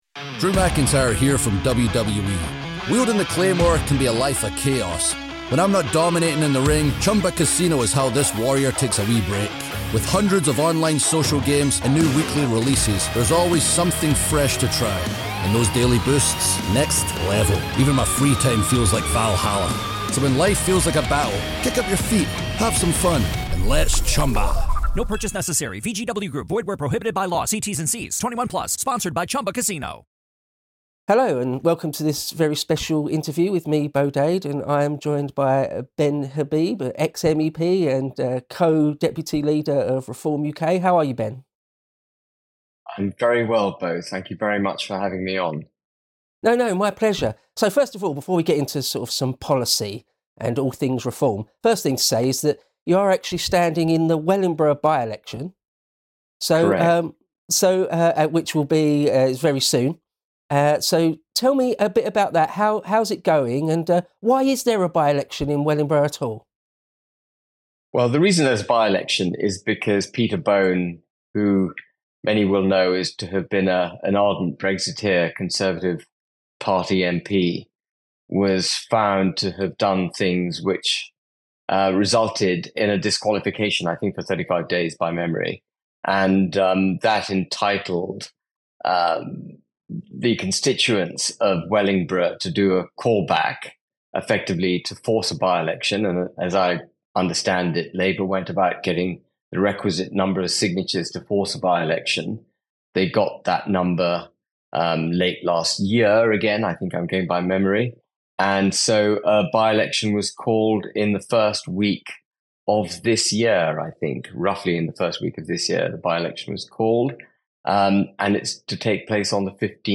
Interview with Ben Habib